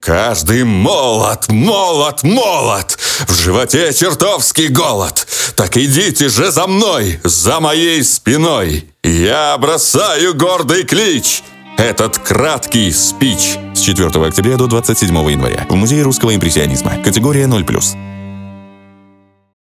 Тракт: Профессиональное звукозаписывающее оборудование, дикторская кабина, запись в сторонней студии